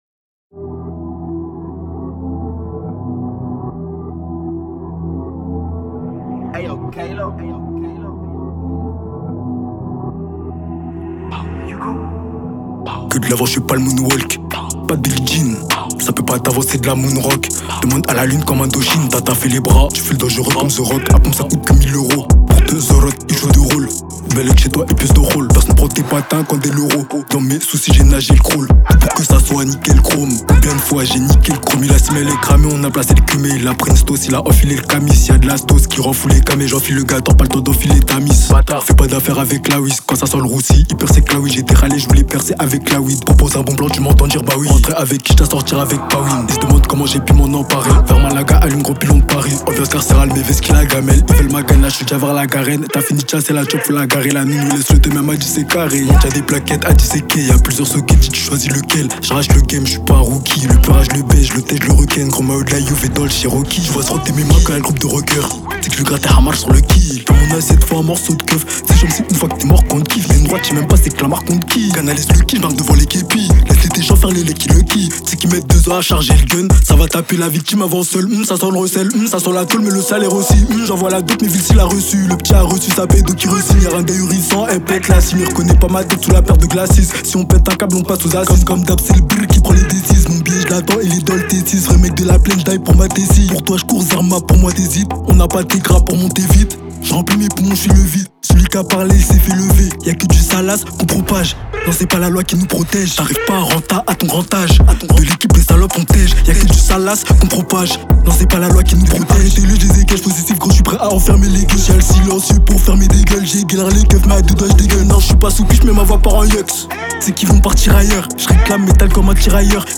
Genre: Blues